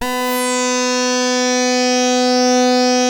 59-SAWRESWET.wav